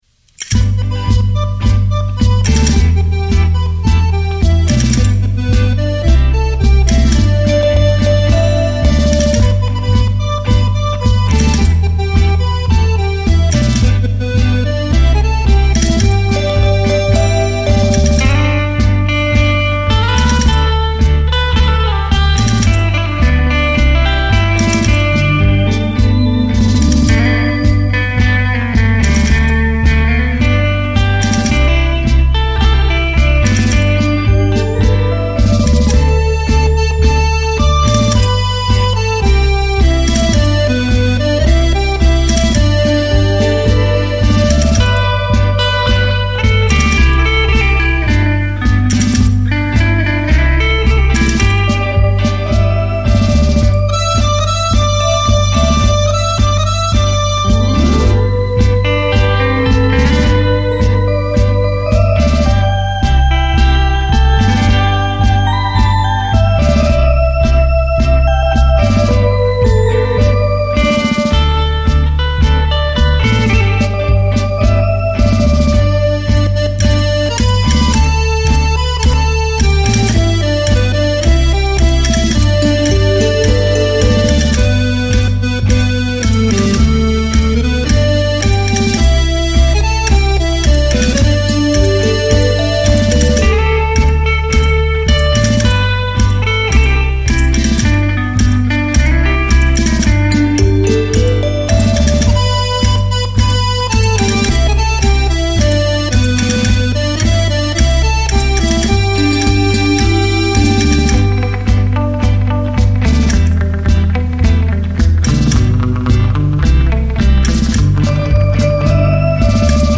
立體效果 環繞身歷聲 超魅力出擊 全新風格精心製作